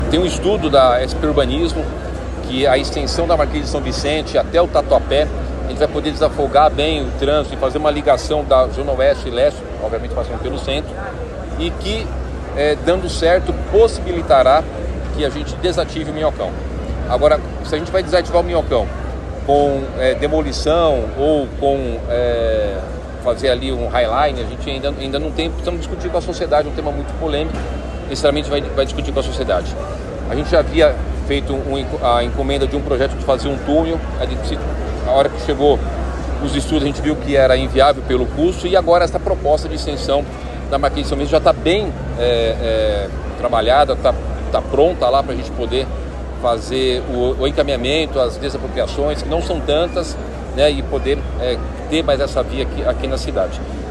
A informação é do prefeito Ricardo Nunes, em entrevista coletiva na manhã desta quarta-feira, 02 de abril de 2025.